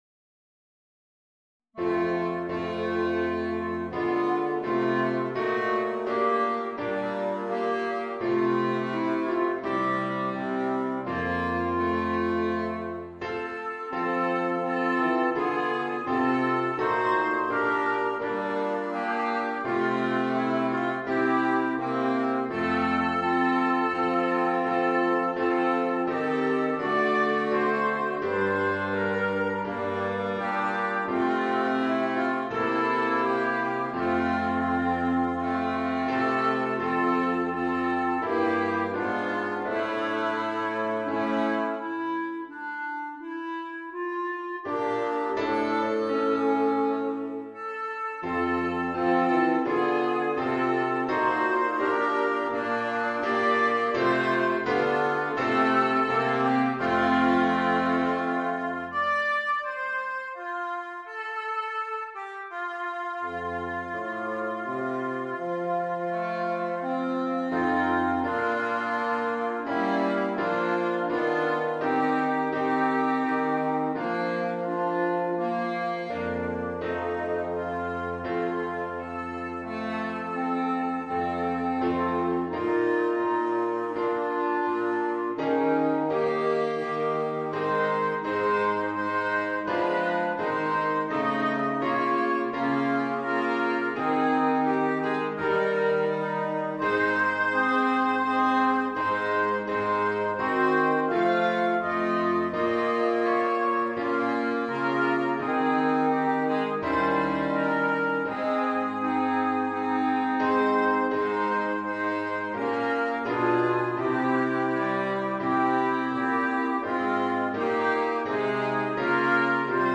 für variables Holzbläserquartett Schwierigkeit